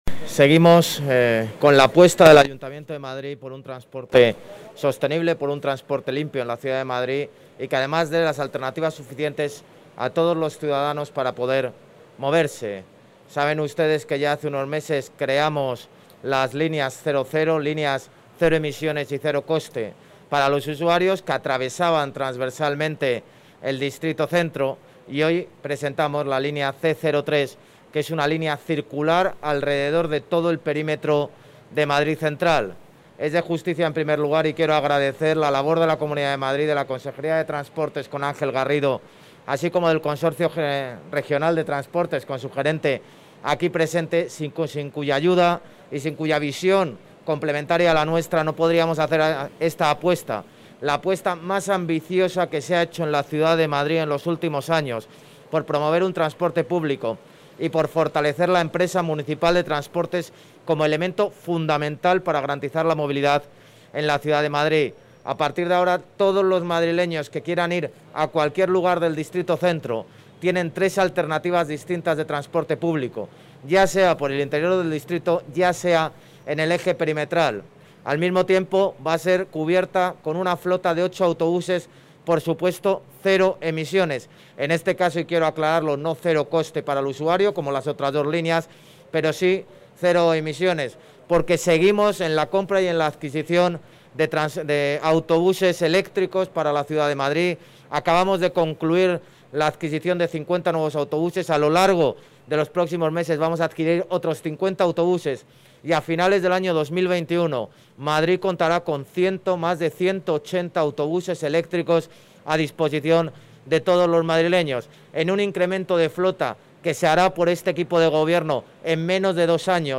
Nueva ventana:José Luis Martínez-Almeida, alcalde de Madrid
(AUDIO) ALCALDE SOBRE NUEVA LINEA PERIMETRAL L3.mp3